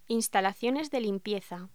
Locución: Instalaciones de limpieza
voz